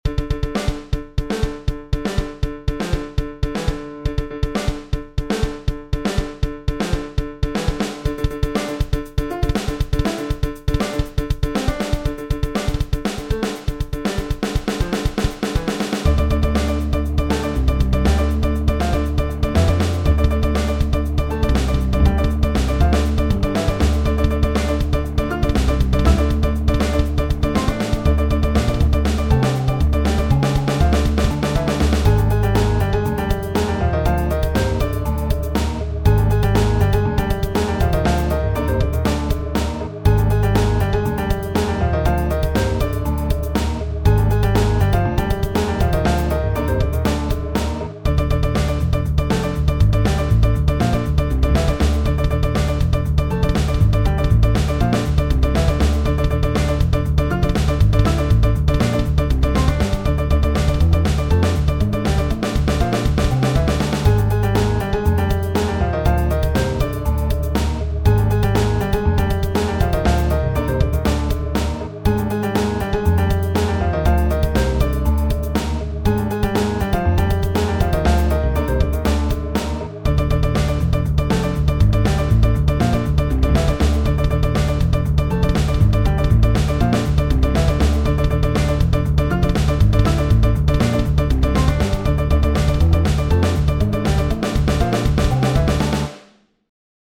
Made using LMMS